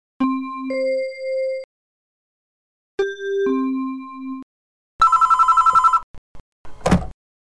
：　シグナル音（PreQ前とポストQ前、及び電話ベル音
Signal1+2+Bell.wav